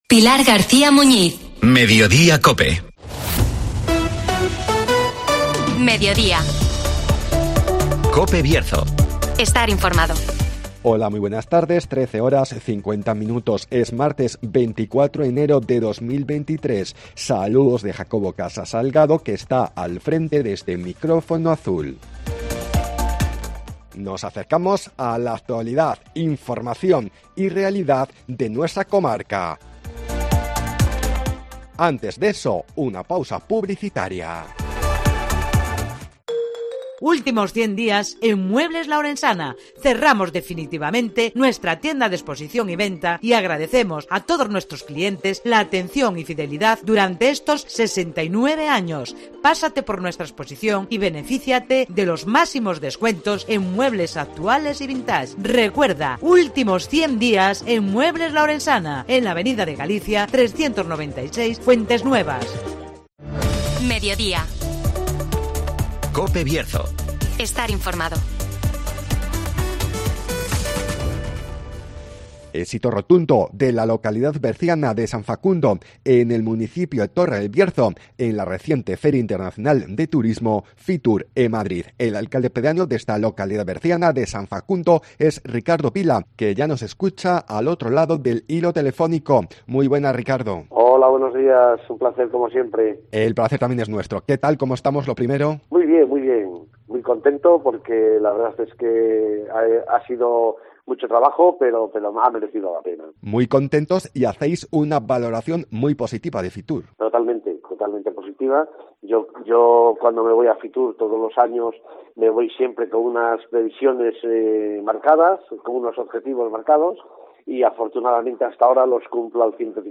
La localidad berciana de San Facundo triunfa en Fitur (Entrevista